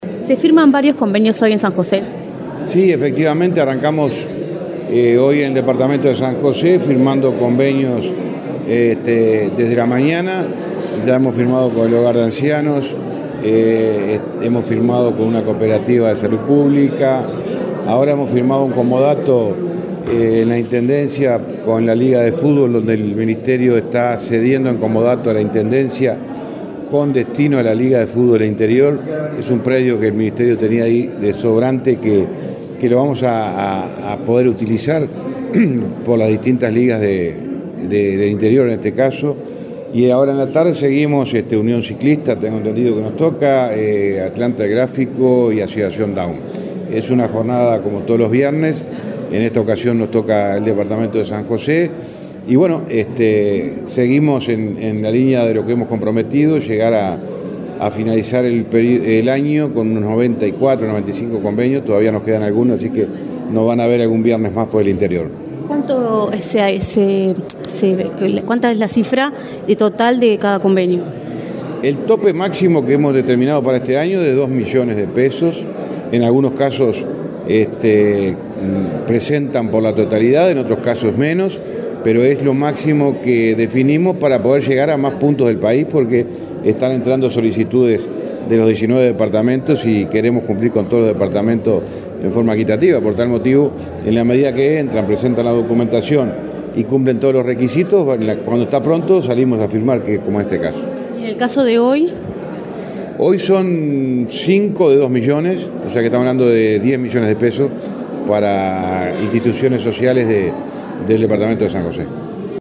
Entrevista al ministro de Transporte, José Luis Falero
El ministro de Transporte, José Luis Falero, dialogó con Comunicación Presidencial en San José, donde firmó convenios con varias instituciones